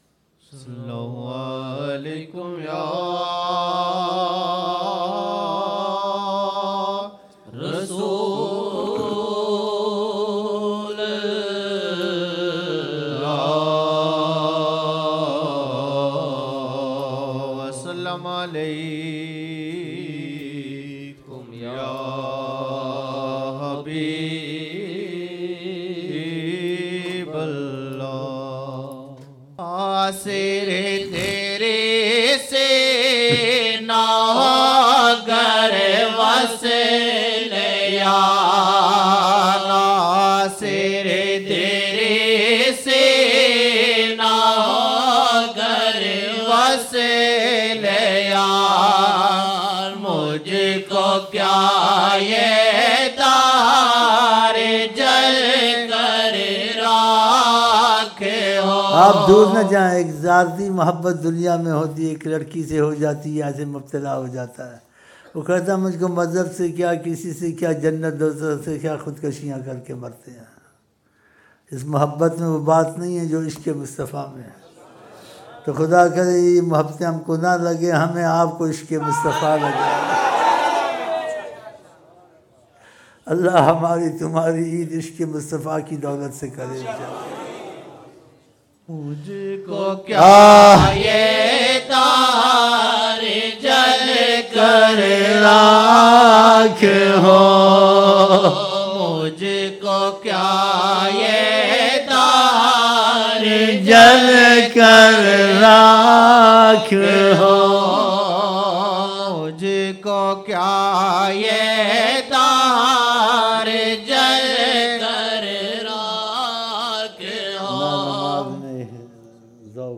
2007-01-02 02 Jan 2007 Old Naat Shareef